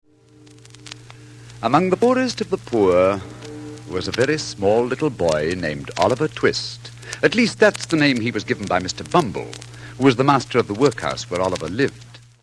Oliver Twist and Fagin - Basil Rathbone as Fagin and narrator (3 records, 78 rpm, Columbia Masterworks MM700, recorded in 1947)